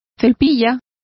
Complete with pronunciation of the translation of chenille.